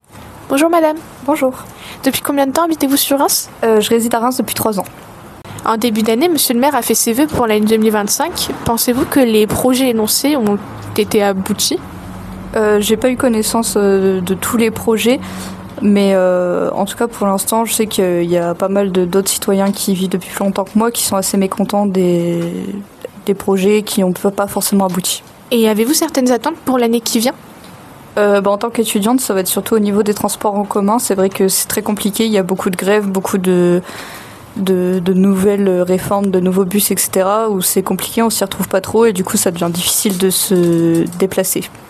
micro-trottoir